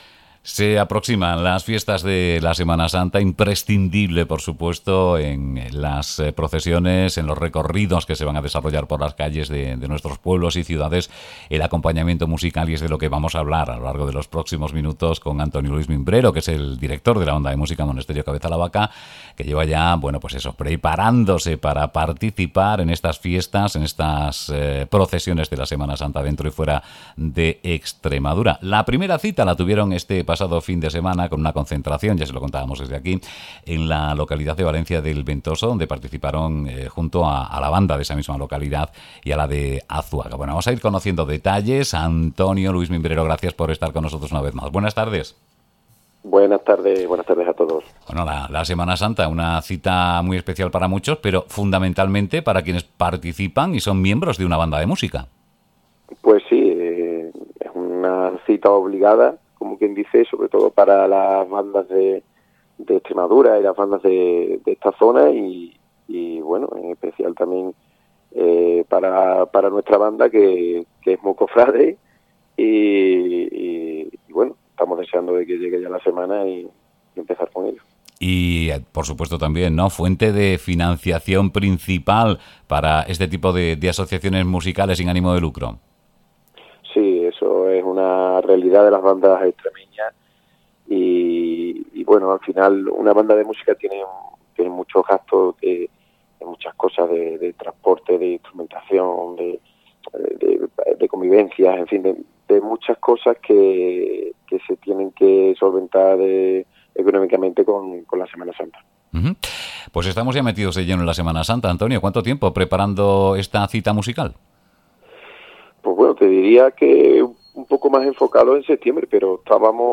La banda de música Monesterio-Cabeza la Vaca despierta la pasión por la música sacra
iINYDBANDASEMANASANTA.mp3